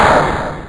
Pow.mp3